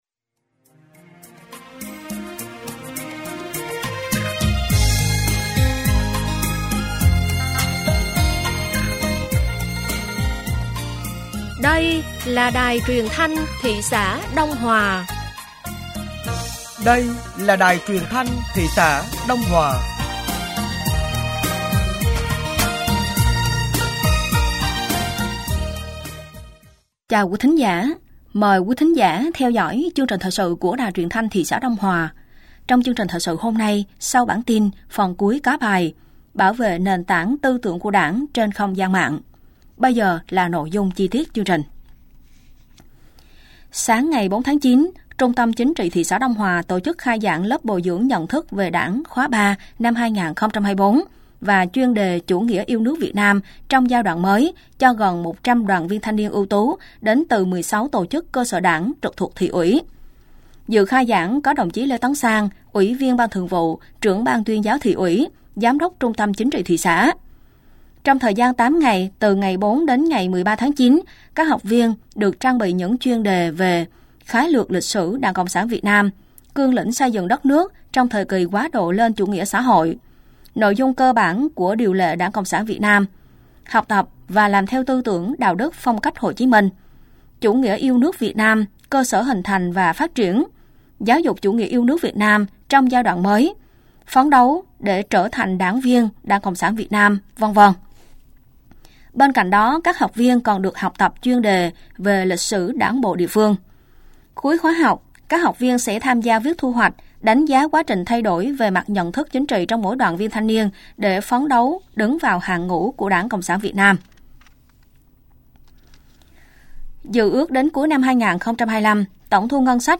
Thời sự tối ngày 04 và sáng ngày 05 tháng 9 nămn 2023